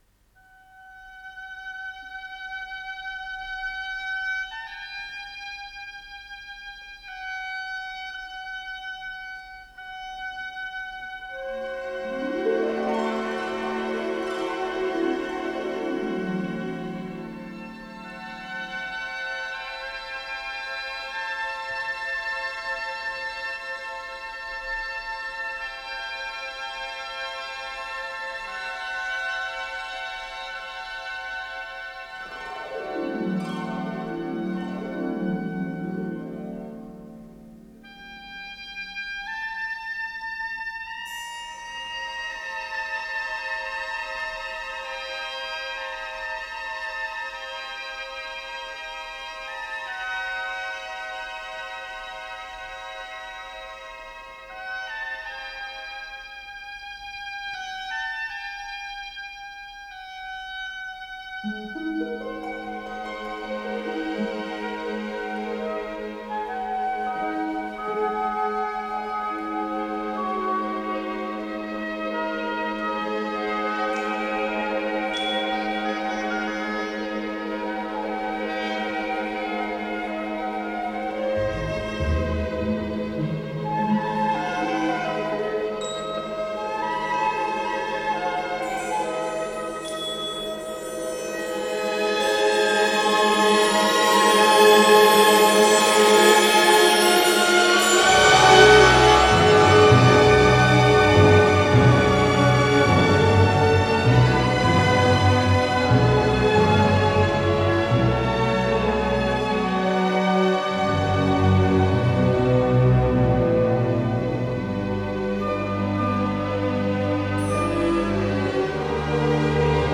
Скорость ленты38 см/с
ВариантДубль моно